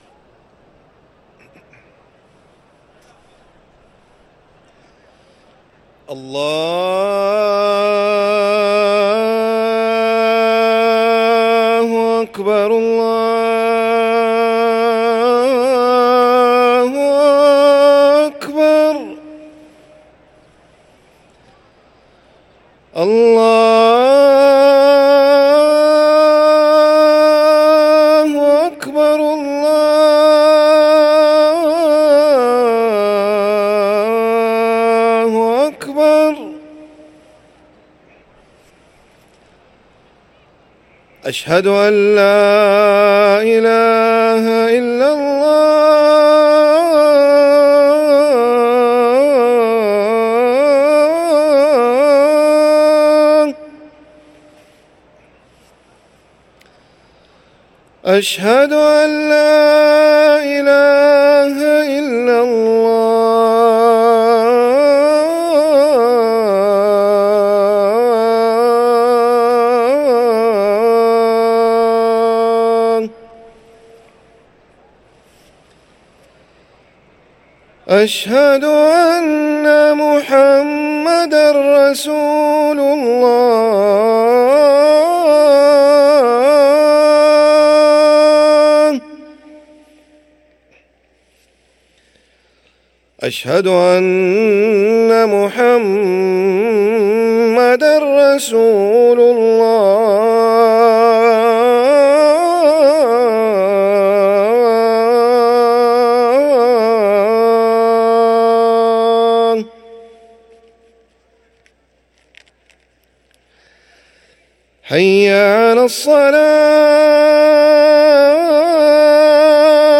أذان المغرب